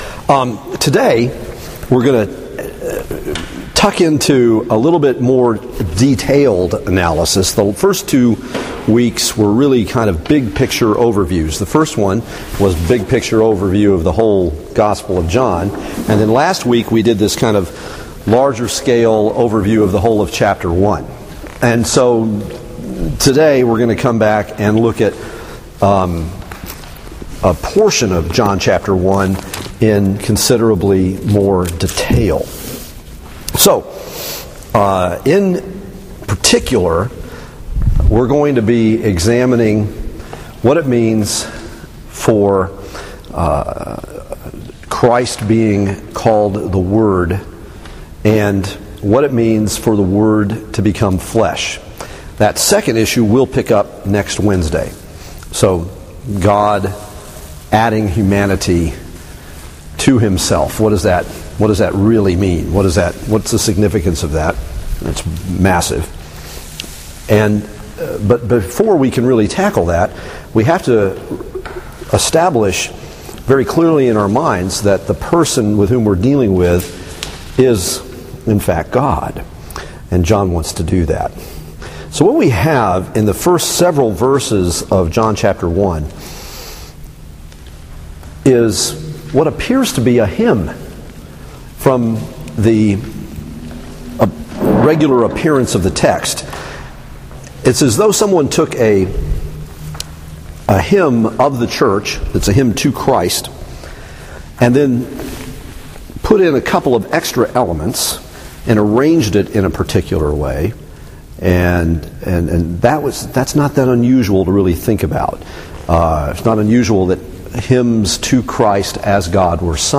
Passage: John 1:1-5, 14-18 Service Type: Bible Study